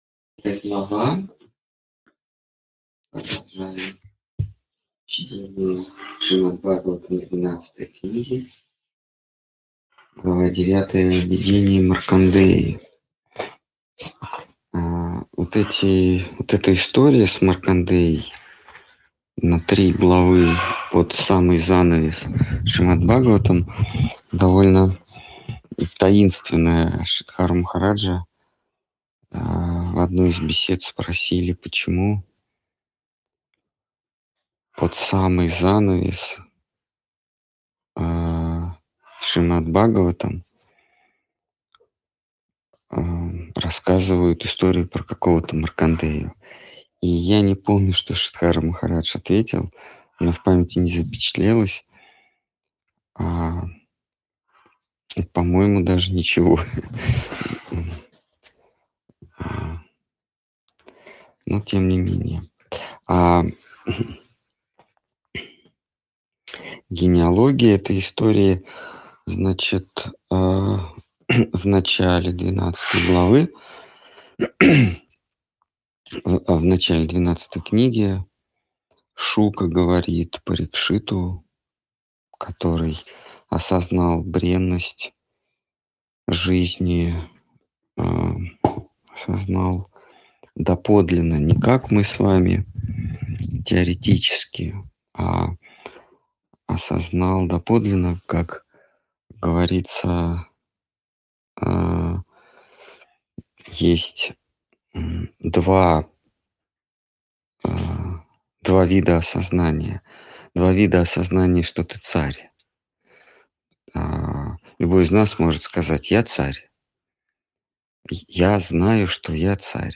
Ответы на вопросы из трансляции в телеграм канале "Колесница Джаганнатха". Тема трансляции: Шримад Бхагаватам. Книга 12. Глава 9. Видение Маркандеи.